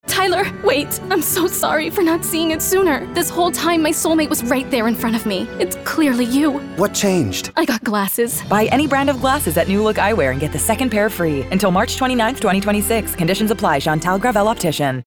Commercial (NewLook) - EN